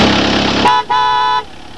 These were all recorded with the microphone placed under the hood right next to the engine.
Horn
HORN.WAV (39,410 bytes) - The sound of an ECOdiesel's dual-tone horn.
horn.wav